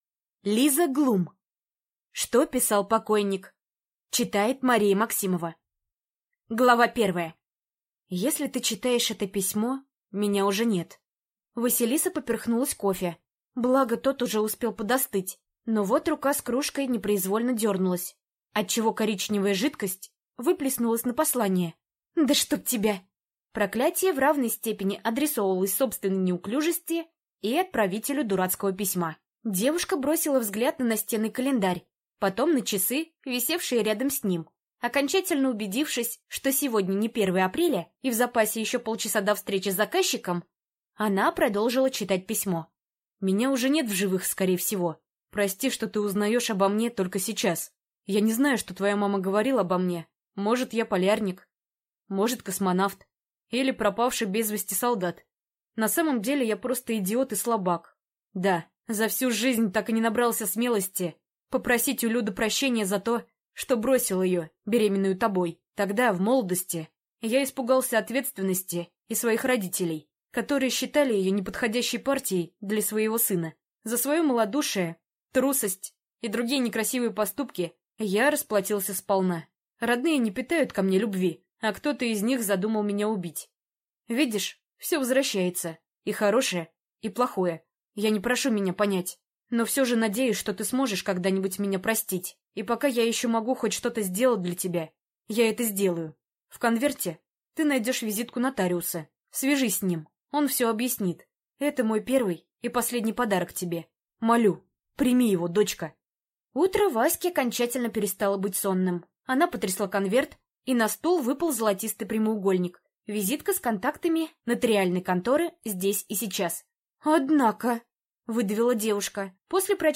Аудиокнига Что писал покойник | Библиотека аудиокниг